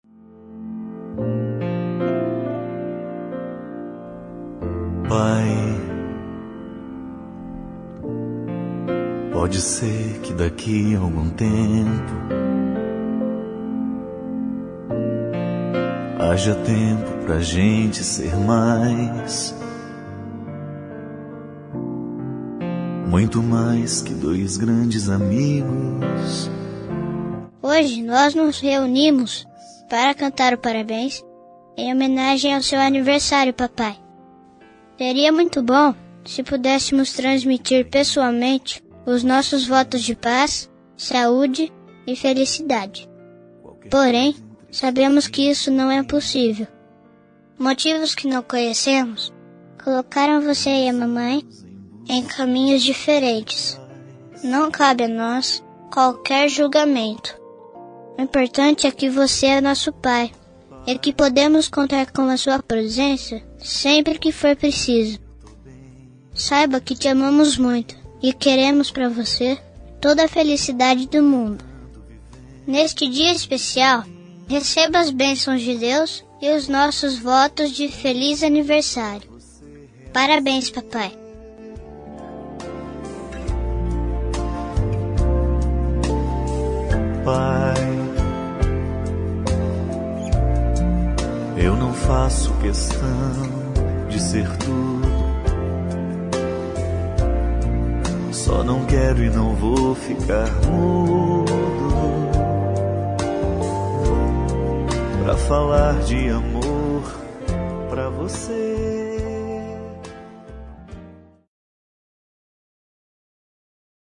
Aniversário Voz Infantil – Pai Separado – Voz Masculina – Cód: 258732